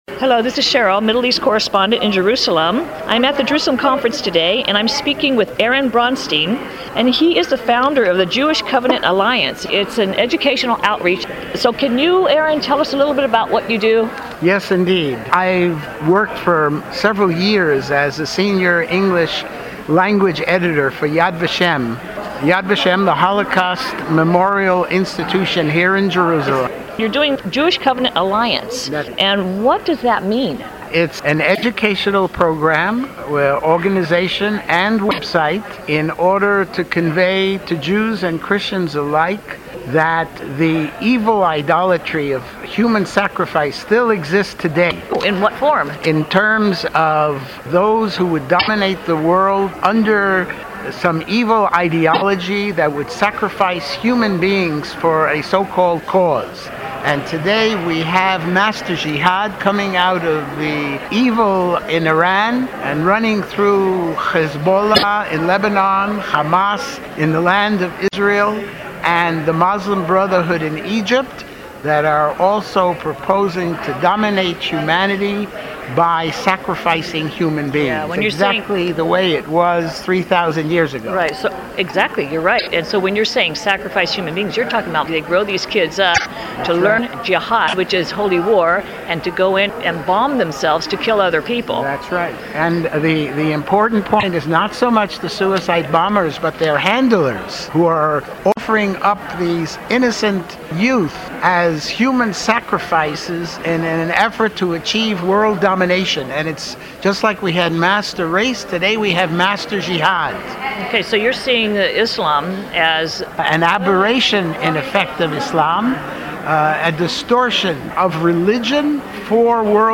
HABN radio interview.mp3